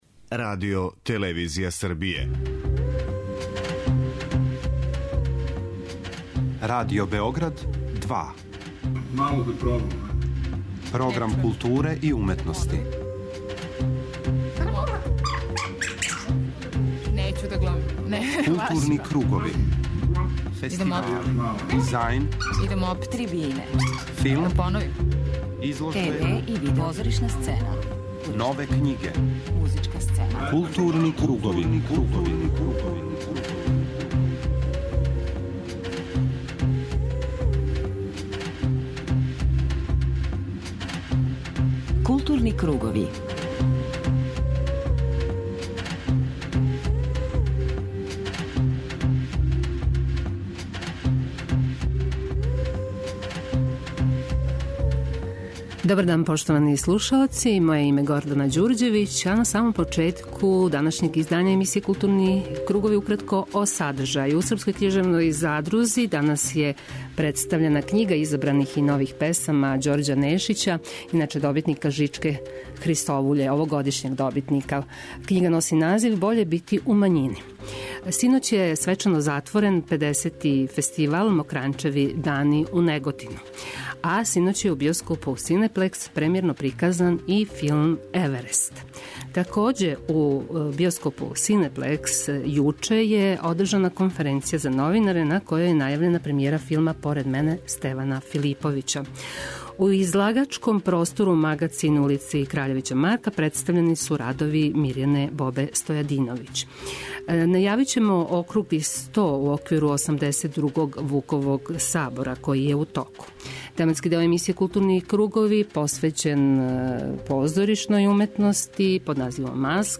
преузми : 52.78 MB Културни кругови Autor: Група аутора Централна културно-уметничка емисија Радио Београда 2.